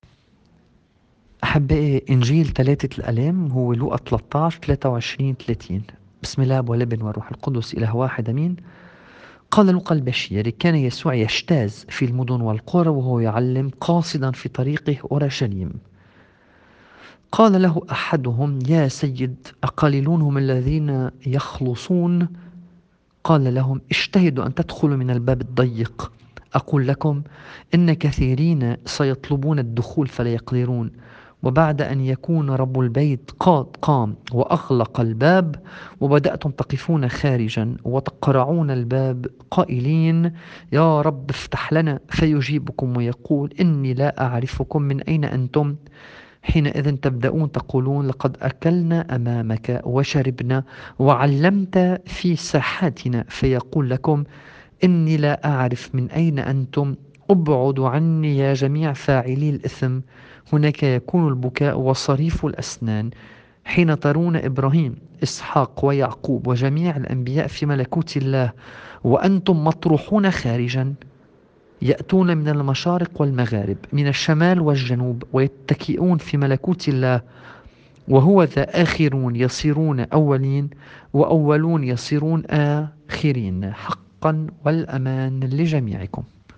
الطقس الماروني